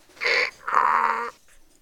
Donkey Bray
This was a donkey bray imitation I did with my mouth, it actually turned pretty good.